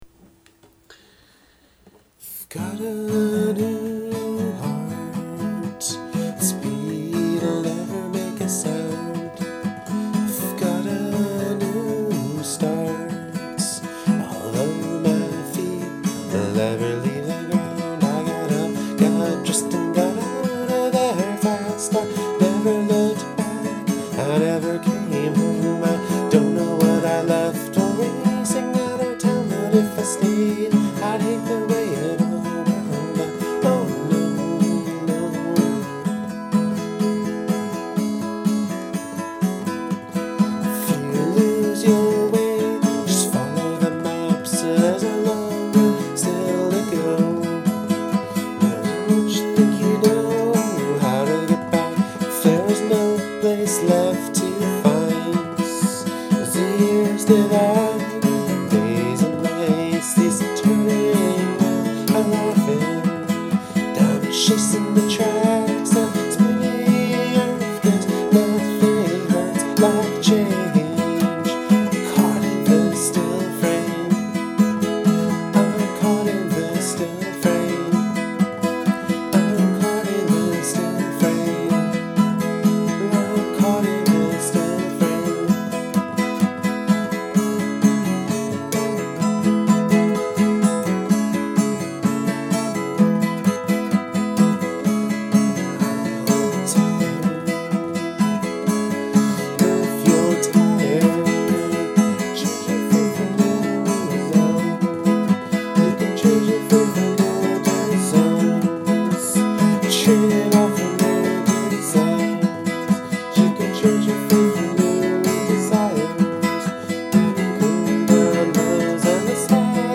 Very, very raw.